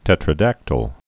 (tĕtrə-dăktəl) also tet·ra·dac·ty·lous (-tə-ləs)